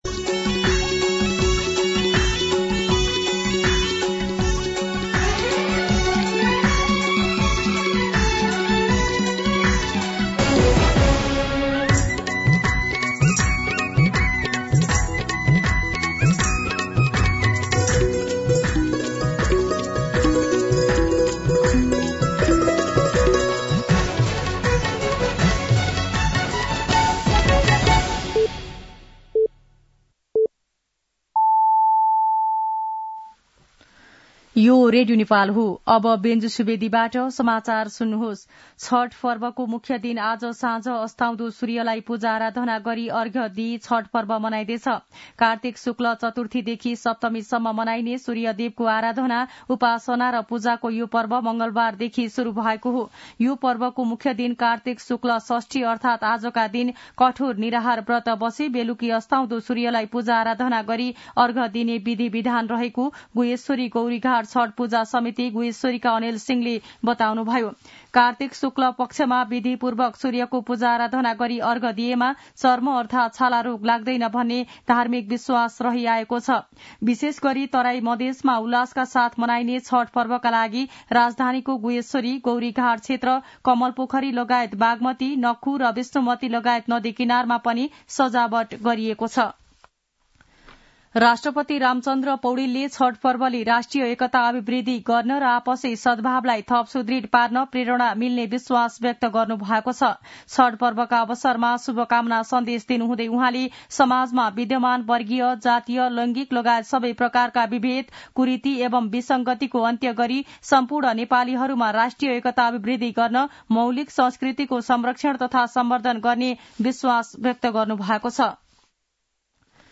दिउँसो १ बजेको नेपाली समाचार : २३ कार्तिक , २०८१
1pm-News-07-22.mp3